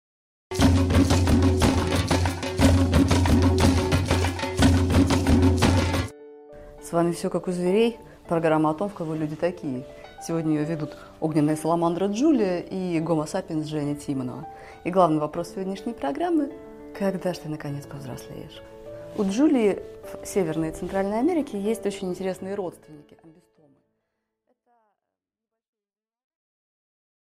Аудиокнига Аксолотль: когда ж ты повзрослеешь?